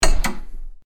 door_unlocking.ogg